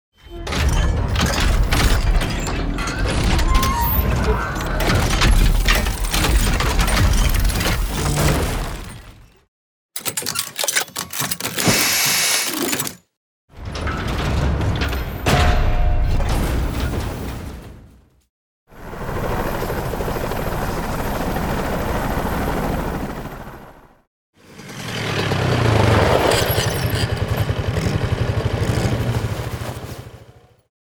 游戏音效[机械类] – 深圳声之浪潮文化传播有限公司
【机械类】是机械类音效，作为声音艺术中的一个重要分支，其应用范围广泛，涵盖了众多领域。